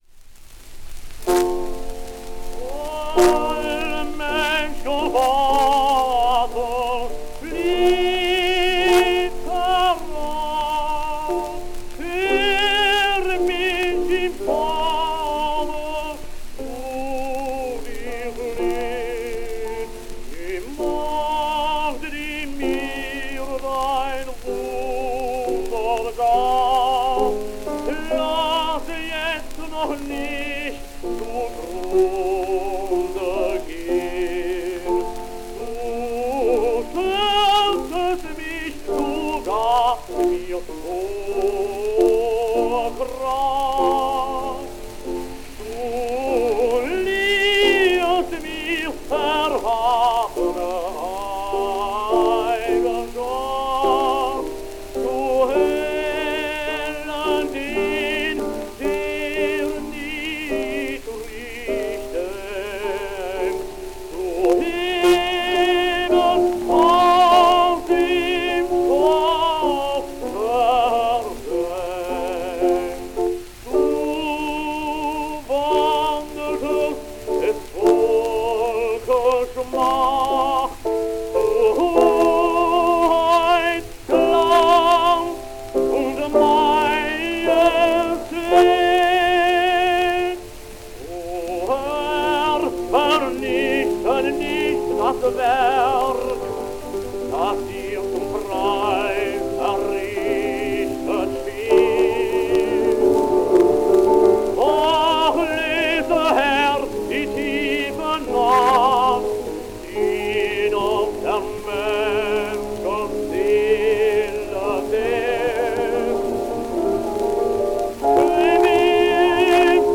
Gramophone/Zonophone, Frankfurt am Main, September 1906